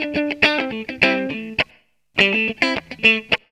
GTR 3  AM.wav